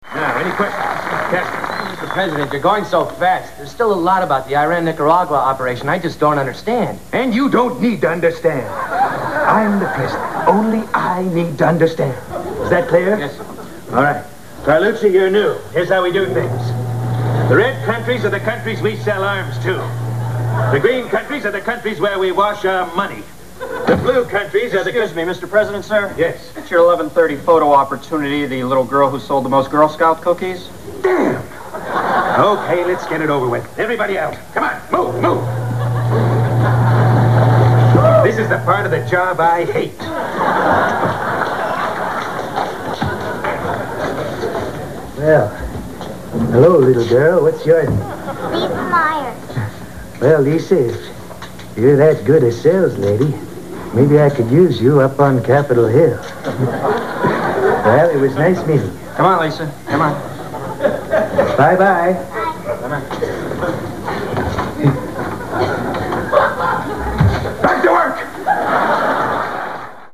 Phil Hartman as Ronald Reagan